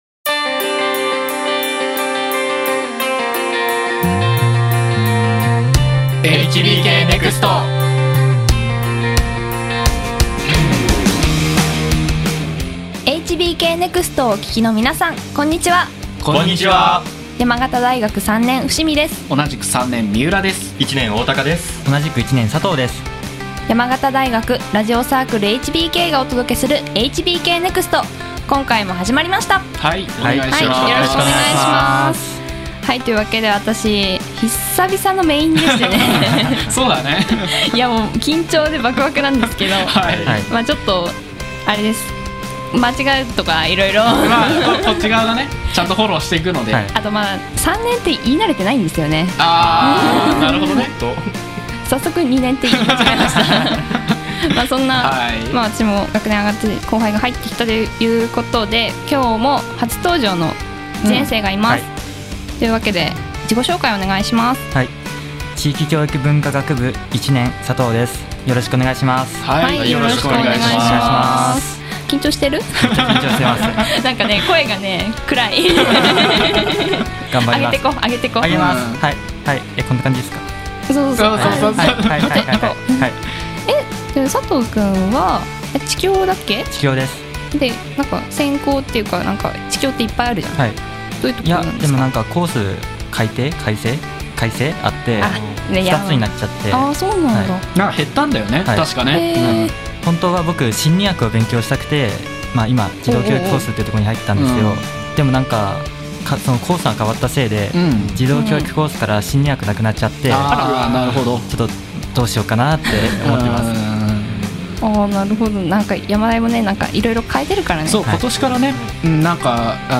2017年4月より始まったラジオサークルHBKのインターネットラジオ番組。 パーソナリティーの愉快なトーク、そして様々な企画をお届けしていきます。